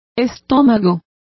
Complete with pronunciation of the translation of bellies.